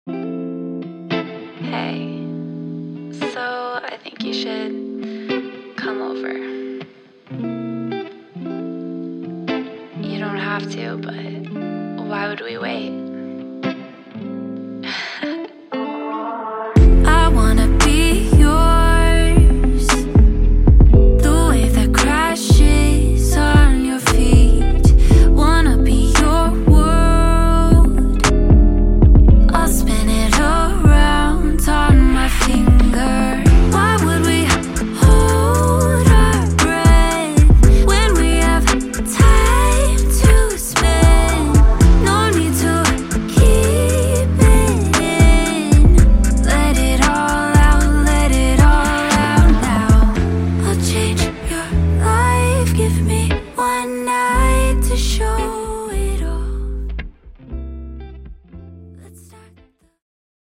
Neo Soul